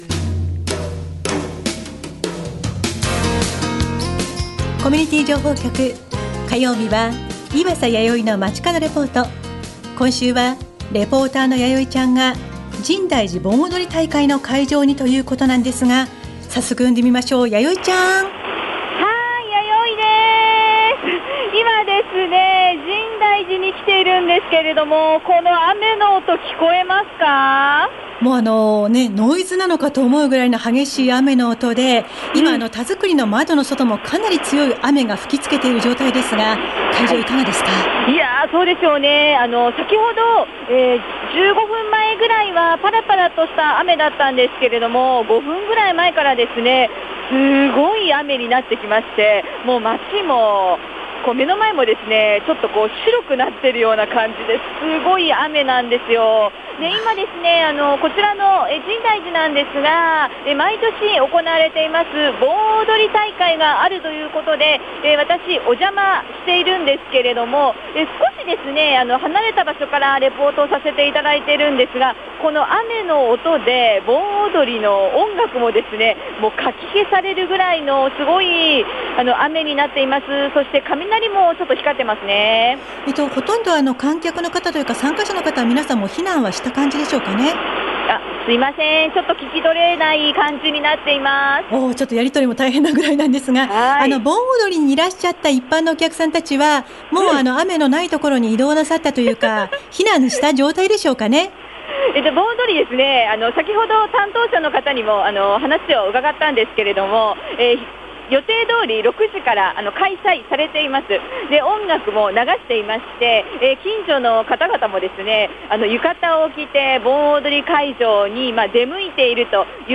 machikado07 今週は、「深大寺盆踊り大会」が開催されているとうことで深大寺に♪ なんと、すごい豪雨でしたー。